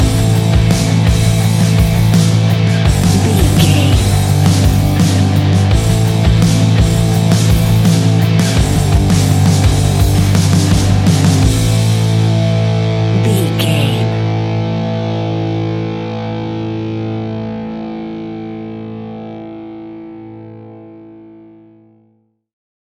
Epic / Action
Dorian
hard rock
heavy rock
blues rock
distortion
instrumentals
Rock Bass
heavy drums
distorted guitars
hammond organ